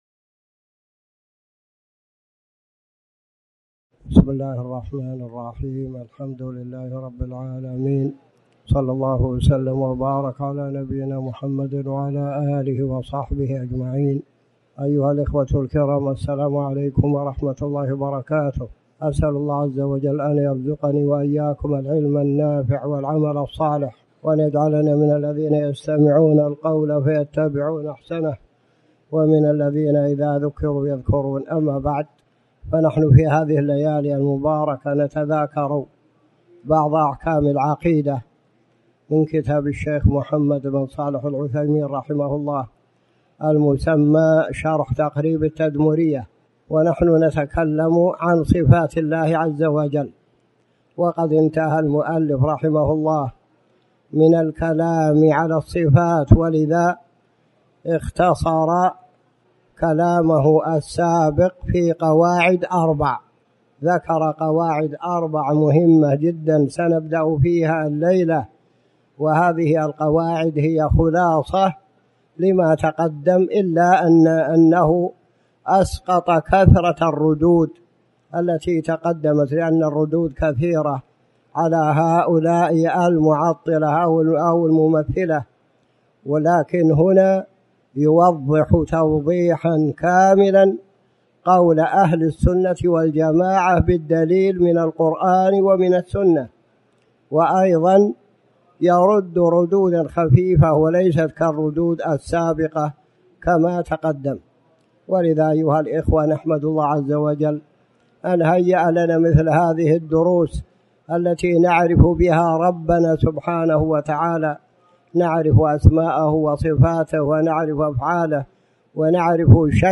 تاريخ النشر ١٩ ذو القعدة ١٤٣٩ هـ المكان: المسجد الحرام الشيخ